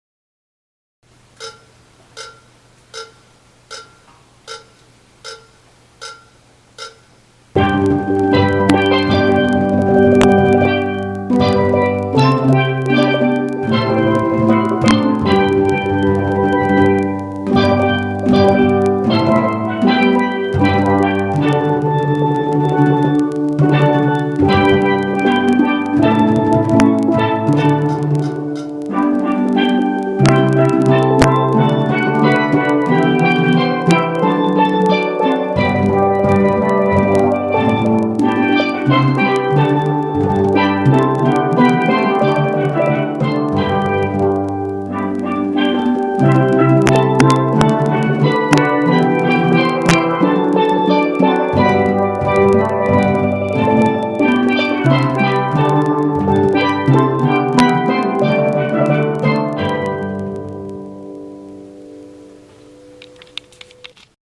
TUTTI 4 PANS
Come again 4 pans .mp3